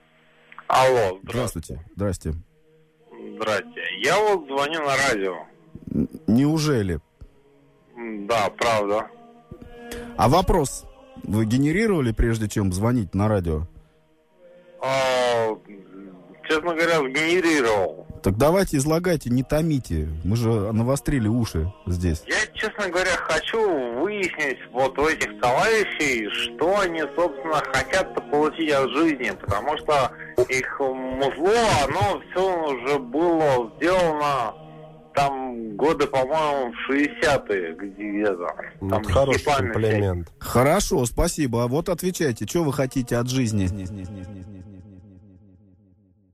Между треками для полноты картины присутсвтуют небольшие бредовые вставочки.